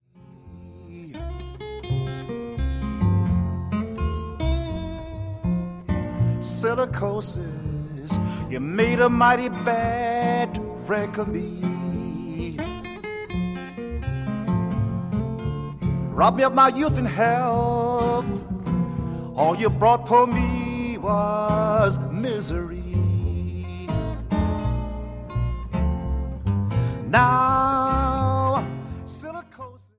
folk-blues singer